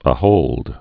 (ə-hōld)